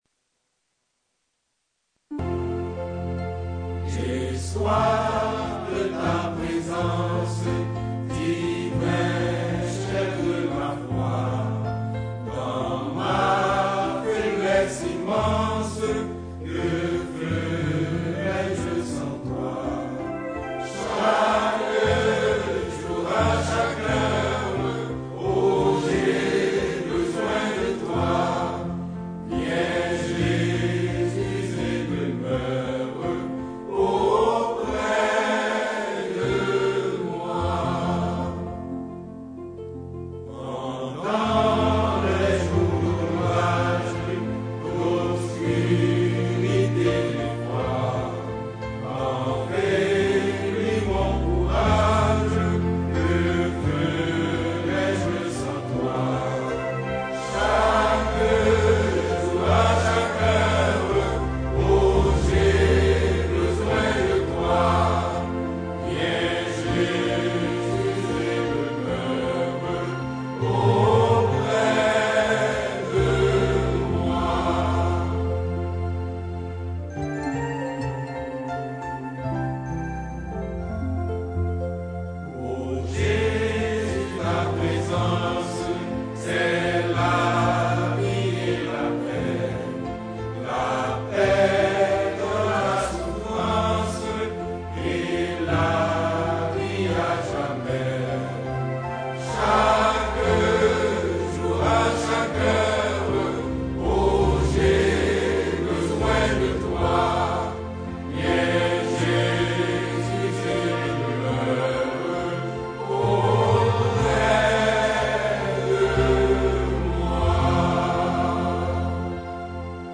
2022 CHANTS MYSTIQUES audio closed https